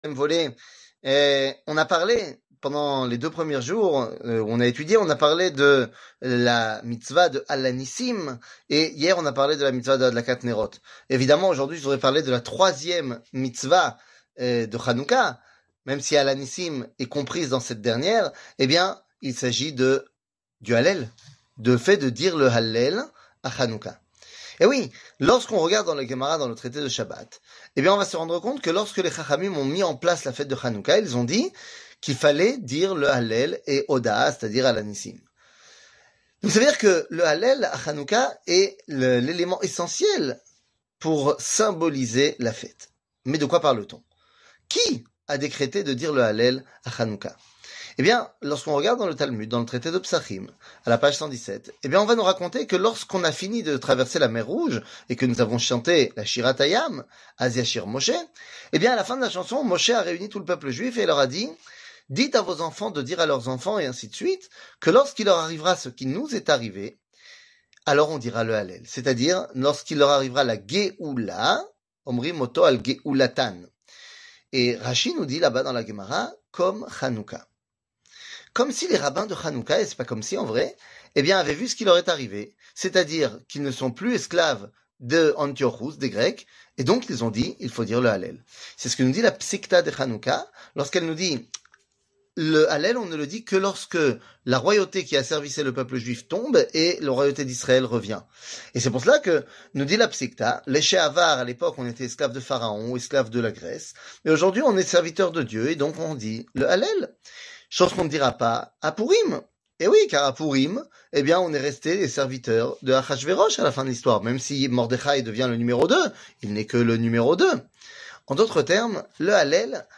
קטגוריה Special Hanouka 3 00:04:54 Special Hanouka 3 שיעור מ 12 דצמבר 2023 04MIN הורדה בקובץ אודיו MP3